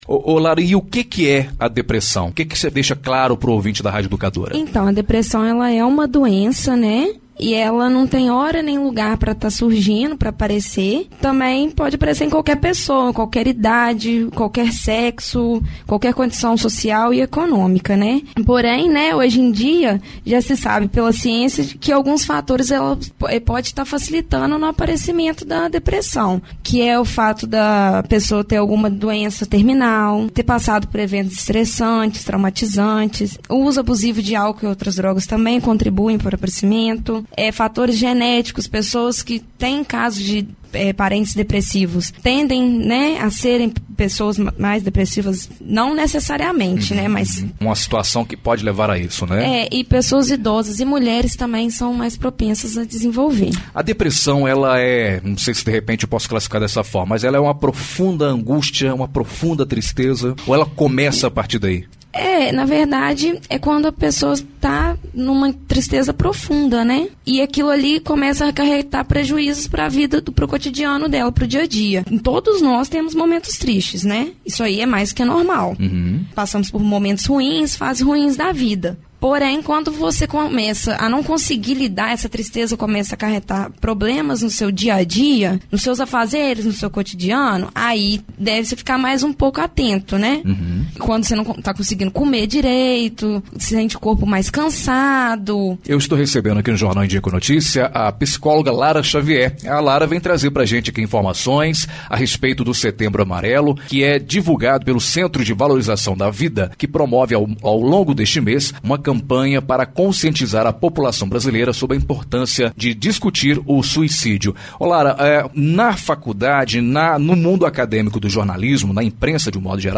Entrevista exibida no Jornal Em dia com a Notícia